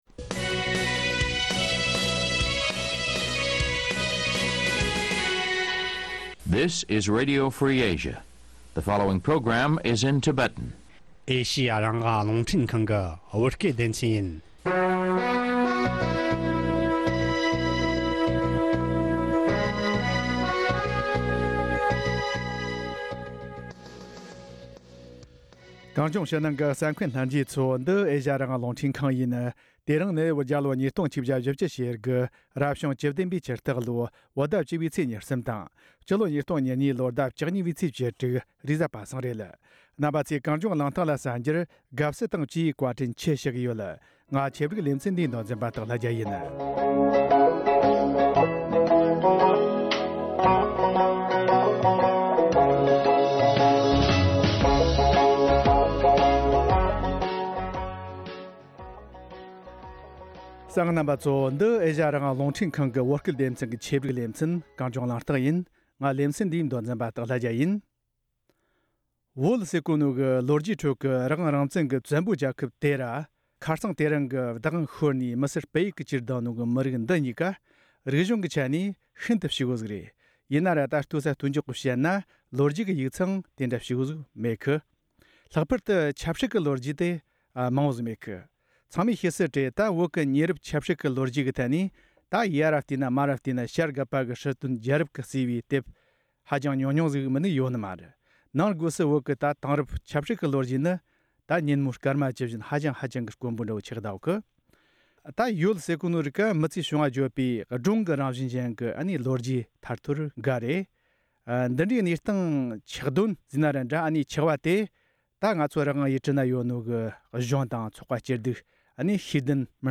བགྲོ་གླེང་བྱས་པར་གསན་རོགས་གནོངས།